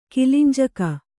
♪ kilinjaka